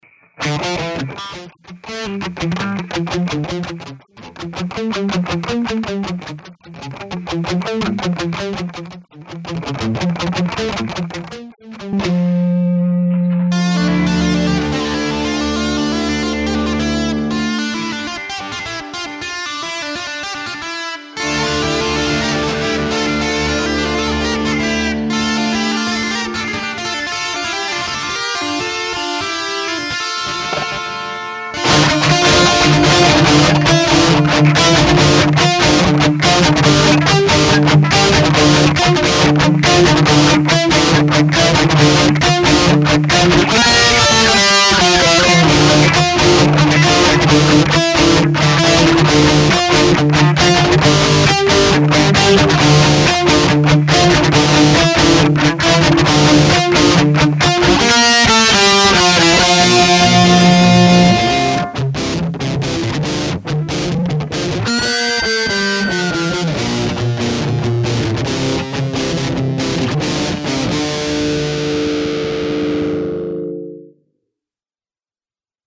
Глюки со звукозаписью так и остались, еле-еле избавился от писка. Гитара в этот раз была перегружена, в некоторых местах - наверное, слишком, но, думаю, при понижении перегруза, падал и сигнал, а он был очень слаб. Хотя, получилось весьма громко.
Думаю, лучше всего слушать оную композицию в наушниках, так как я там немного повыделывался в плане баланса звука.